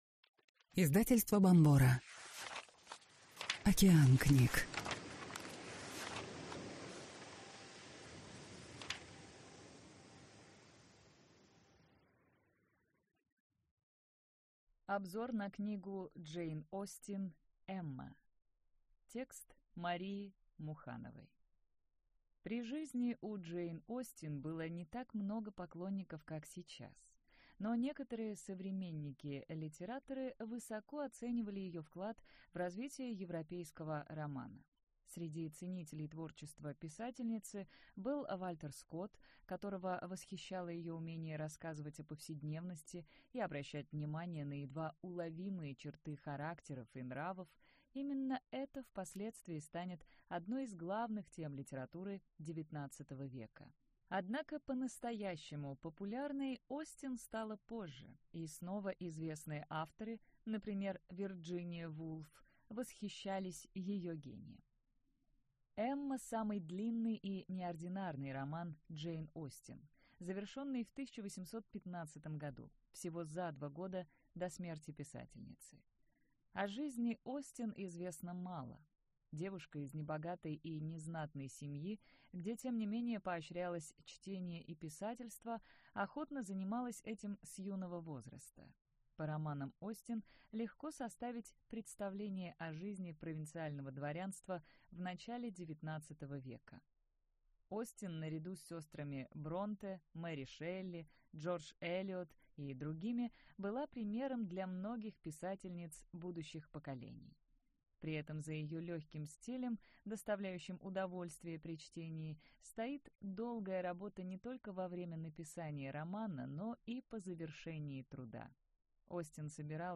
Аудиокнига Саммари книги «Эмма» | Библиотека аудиокниг